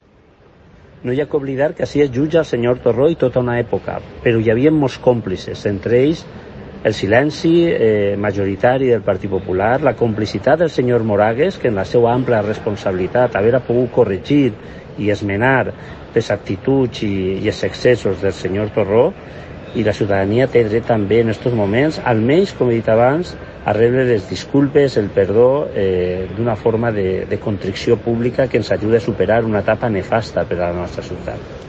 Escucha aquí al socialista